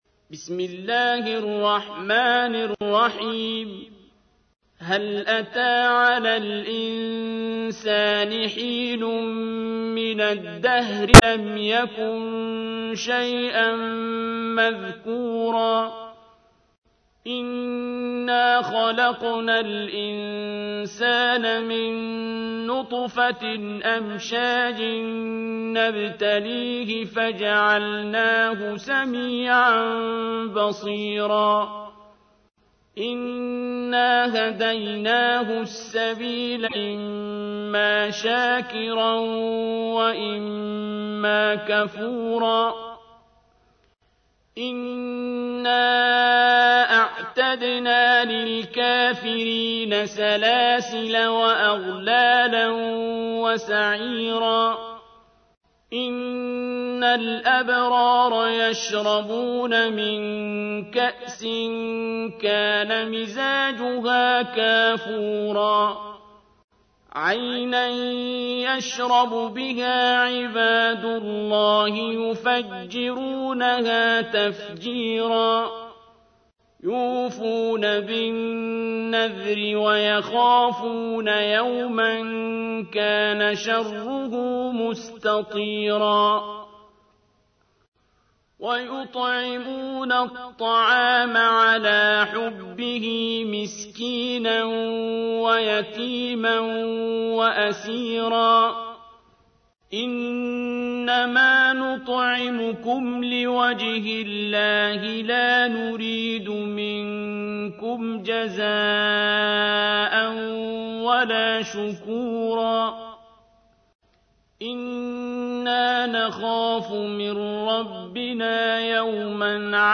تحميل : 76. سورة الإنسان / القارئ عبد الباسط عبد الصمد / القرآن الكريم / موقع يا حسين